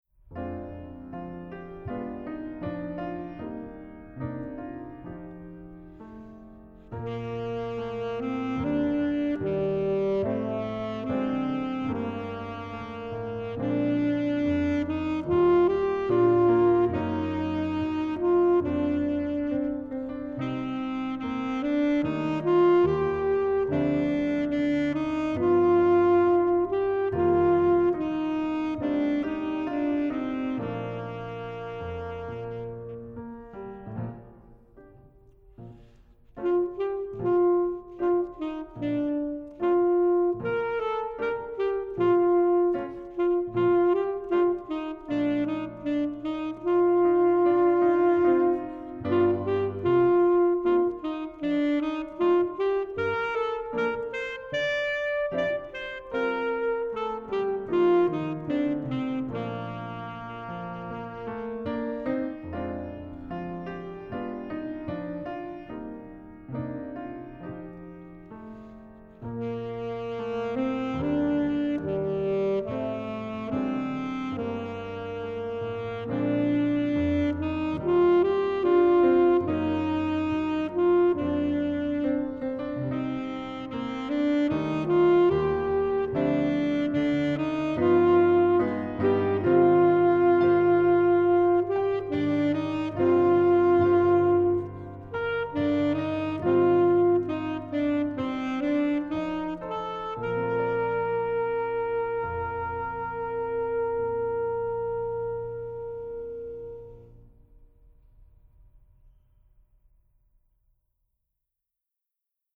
Style:  Lyrical with contrasting March Style Bridge
1        Instrumentation: Alto Saxophone and Piano